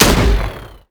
sk03_shot.wav